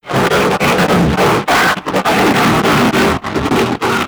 ALIEN_Communication_16_mono.wav